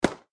pew.mp3